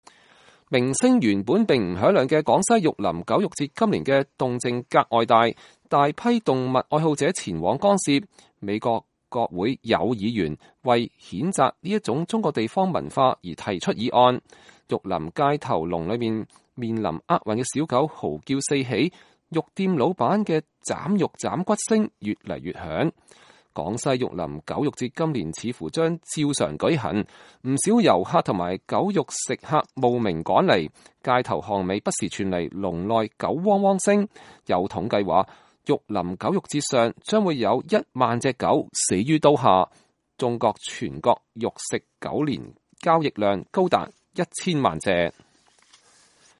廣西狗肉節人畜嘶鳴
玉林街頭籠里面臨厄運的小狗嚎叫四起，肉店老闆的剁肉剁骨聲越來越響。
現場能夠清楚聽得到砰砰的聲音，那是屠夫刀下剁肉和剁骨聲。